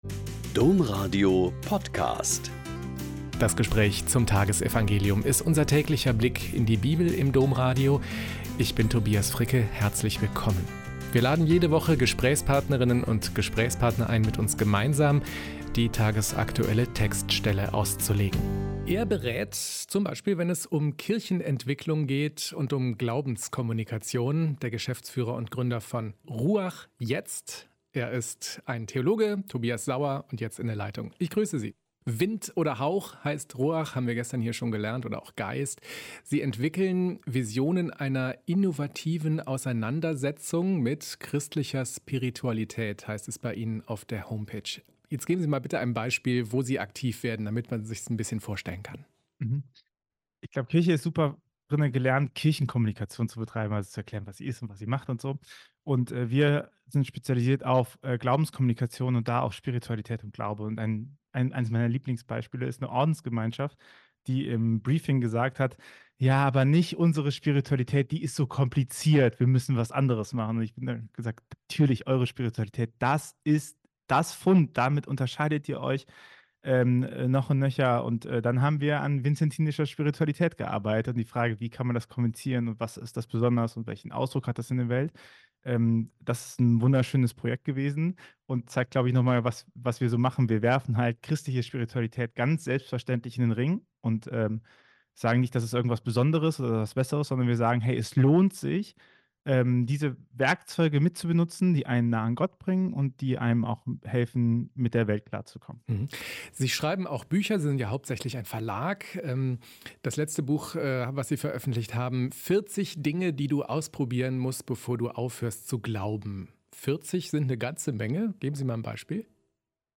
Lk 4,14-22a - Gespräch